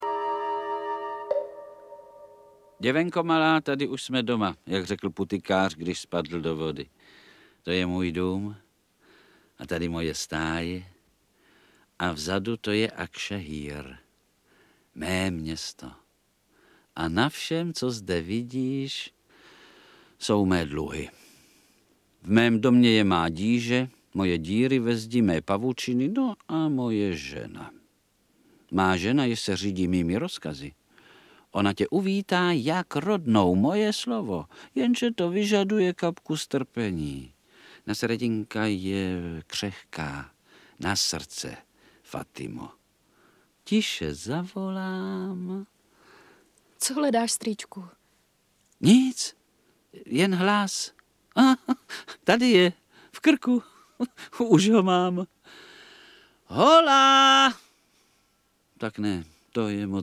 Audiobook
Read: Vlastimil Brodský